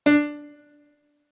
MIDI-Synthesizer/Project/Piano/42.ogg at 51c16a17ac42a0203ee77c8c68e83996ce3f6132